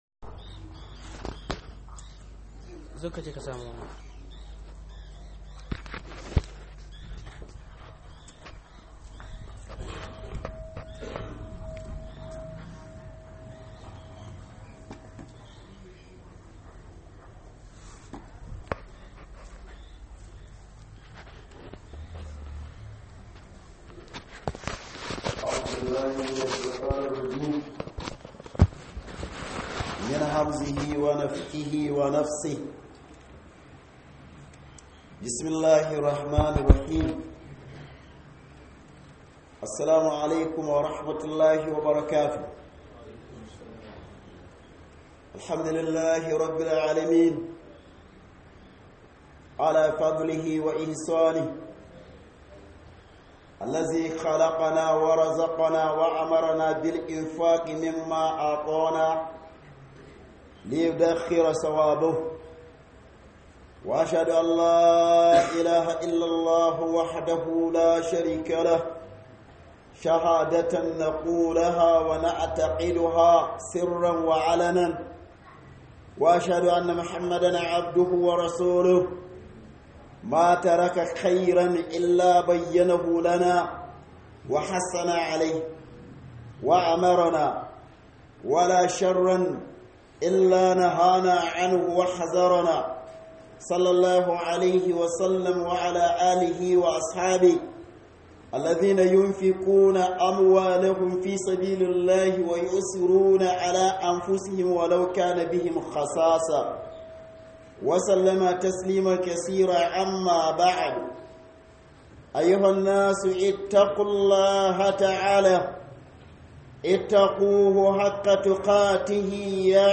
Khuduba - Ibada da dukiya